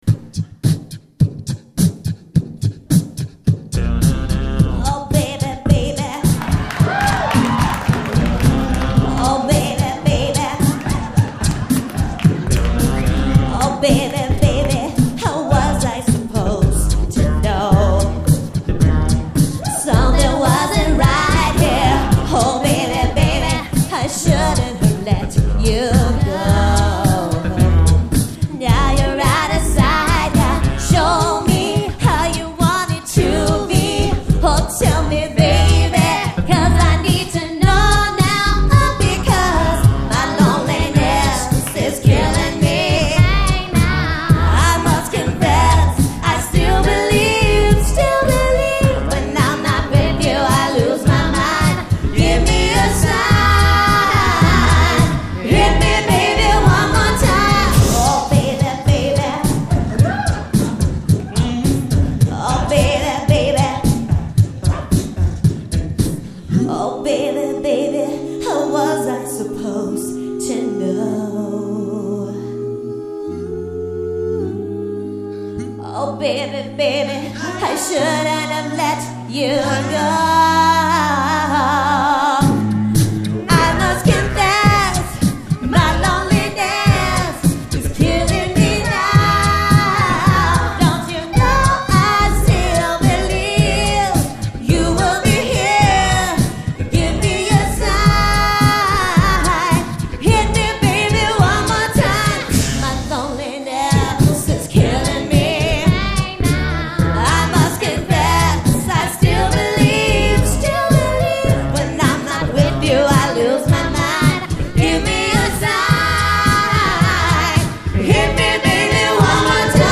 a very sarcastic tribute